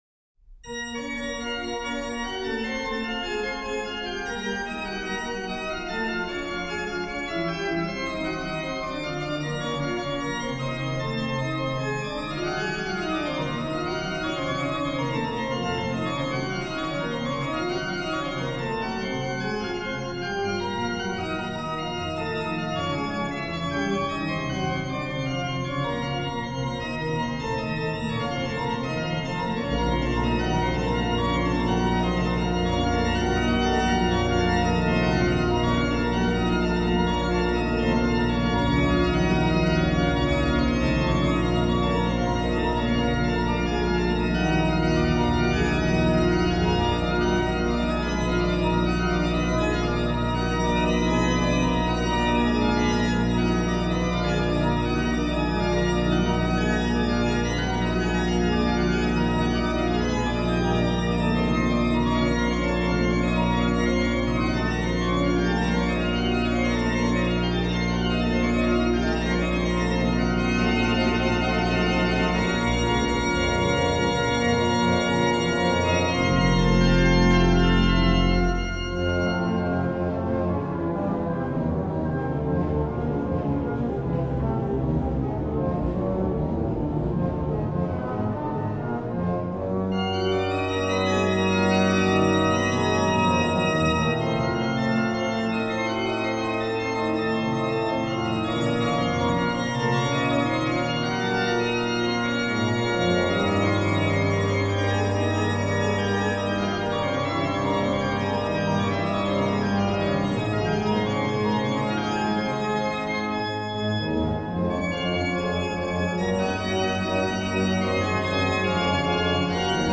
HW: Pr16, Oct8, Ged8, Oct4, Oct2, BW/HW
BW: Fl8, Oct4, Oct2, Scharff
Ped: Pr16, Oct8, Oct4, Rausch, Pos16
m. 25: Ped: + Tr8